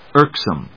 irk・some /ˈɚːksəmˈəːk‐/
• / ˈɚːksəm(米国英語)
• / ˈəːksəm(英国英語)